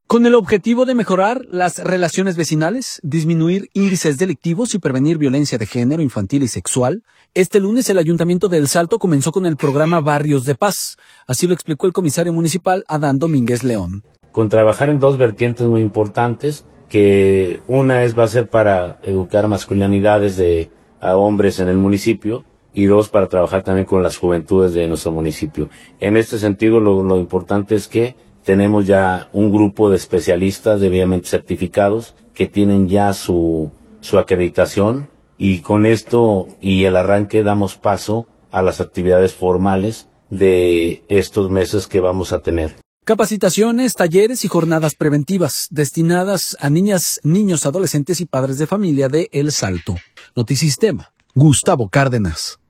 Con el objetivo de mejorar las relaciones vecinales, disminuir índices delictivos y prevenir violencia de género, infantil y sexual, este lunes el Ayuntamiento de El Salto comenzó con el programa “Barrios de Paz”, así lo explicó el comisario municipal, Adán Domínguez León.